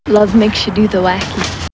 These wavs have been recorded by me and are of a high quality.